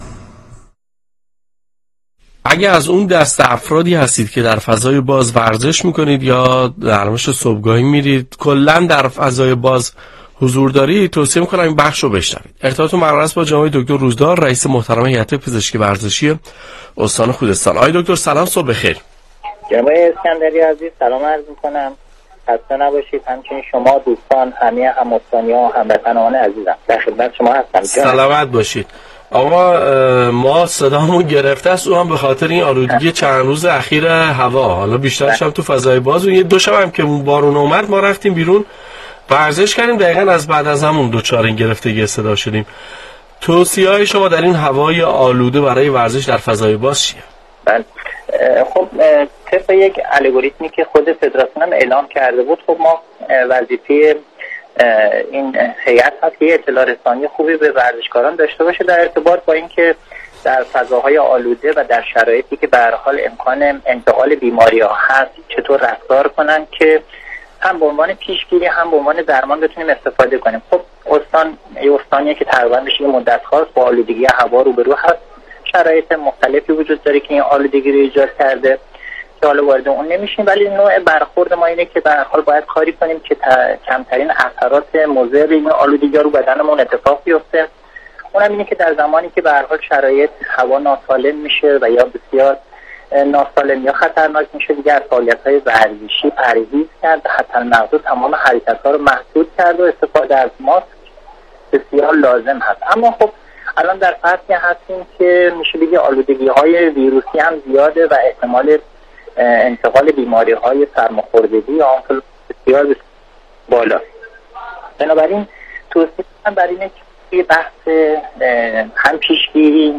/ گفتگویی رادیویی /